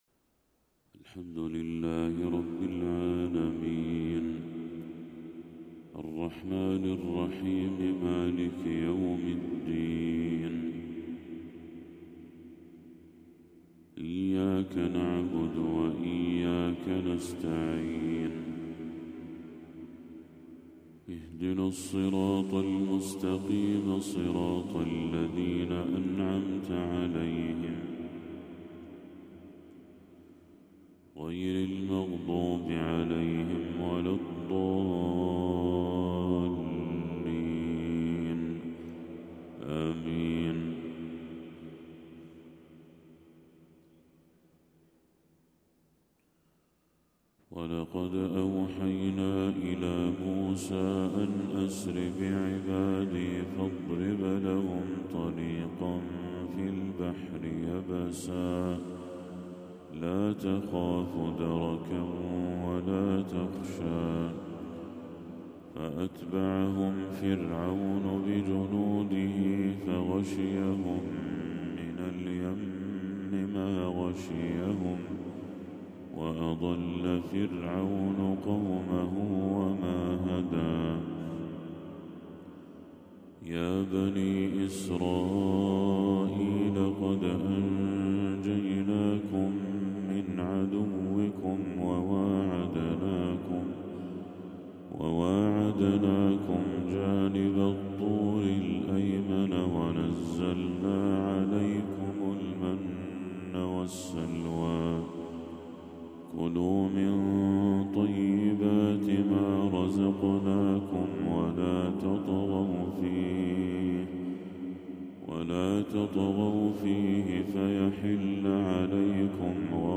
تلاوة خاشعة من سورة طه للشيخ بدر التركي | فجر 28 ربيع الأول 1446هـ > 1446هـ > تلاوات الشيخ بدر التركي > المزيد - تلاوات الحرمين